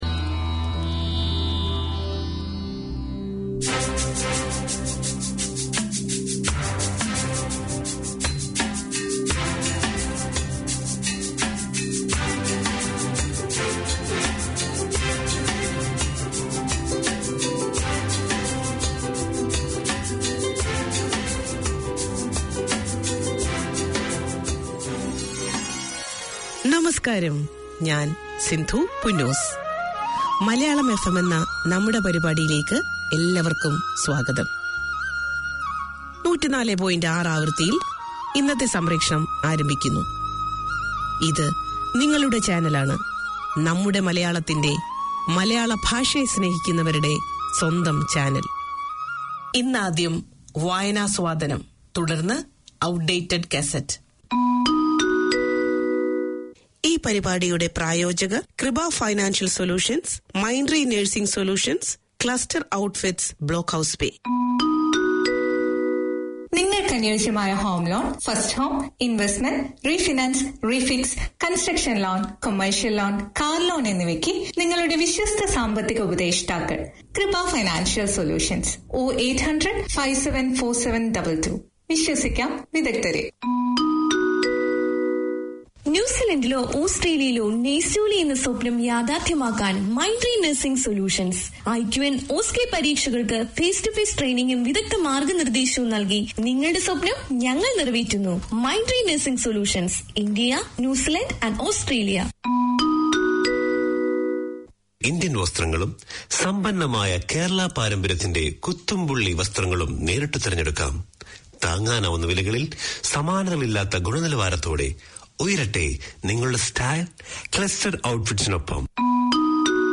Community Access Radio in your language - available for download five minutes after broadcast.